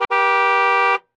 honk1.ogg